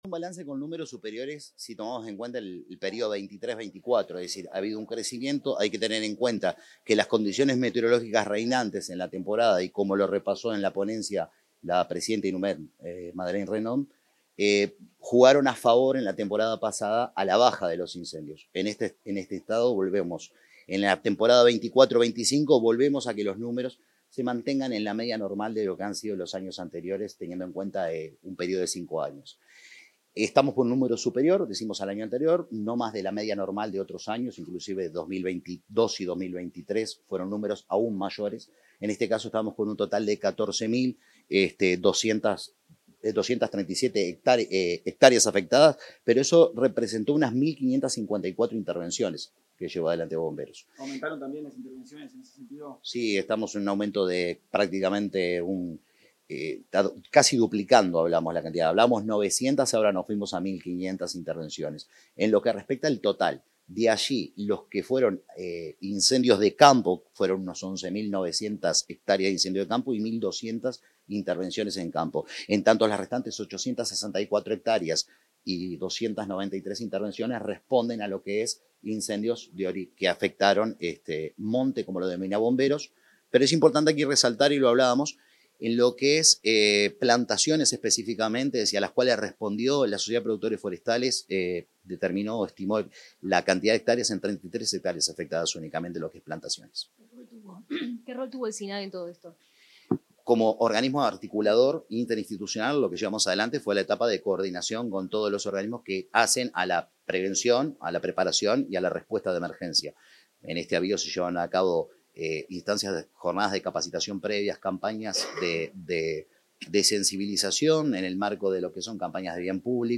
El director del Sistema Nacional de Emergencias (Sinae), Leandro Palomeque, dialogó con los medios de prensa en la Torre Ejecutiva, luego de